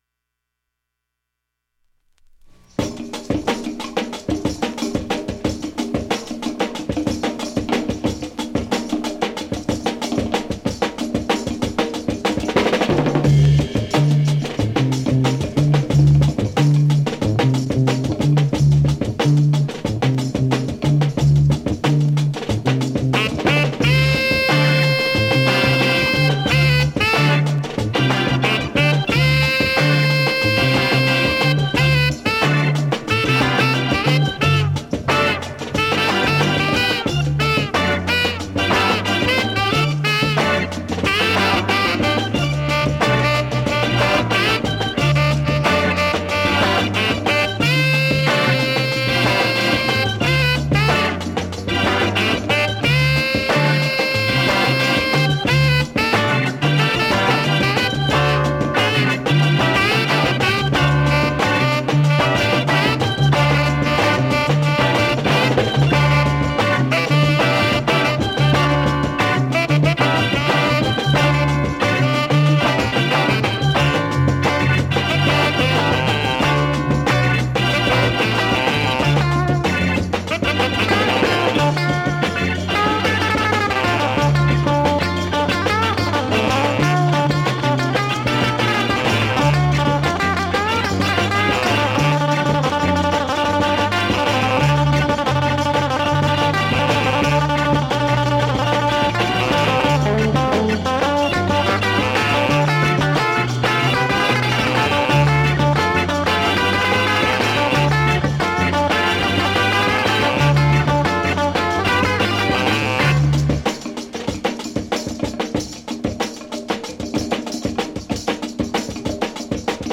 現物の試聴（両面すべて録音時間5分46秒）できます。